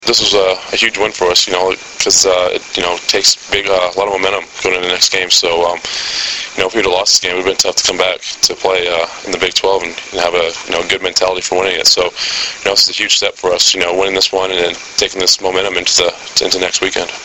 Below are links to audio segments of interviews done with Husker players and head coach Bill Callahan after Nebraska's 37-14 win over Colorado.